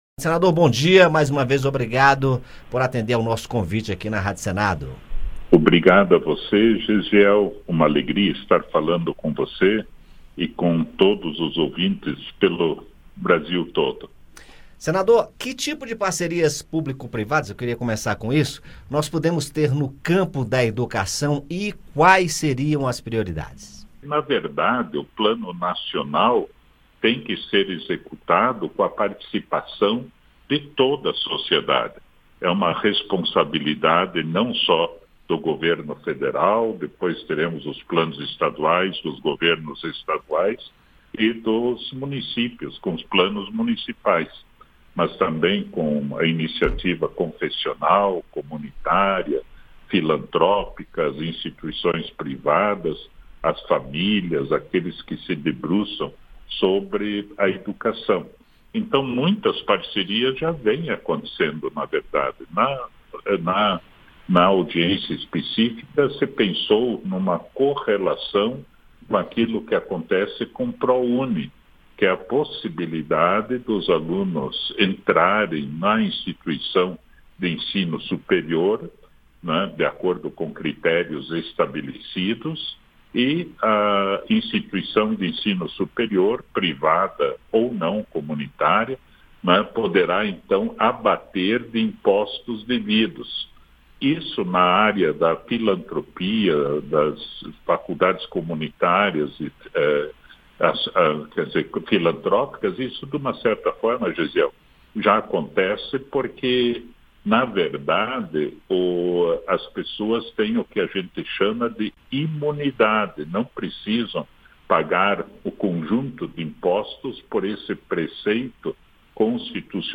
Em entrevista ao Conexão Senado, ele fala sobre o projeto de lei do novo Plano Nacional de Educação para o período de 2024 a 2034. Arns destaca o papel das parcerias público-privadas (PPPS), as prioridades para uma educação mais inclusiva e equitativa no Brasil e o restabelecimento da meta de investimento público na educação em 10% do produto interno bruto (PIB).